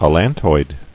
[al·lan·toid]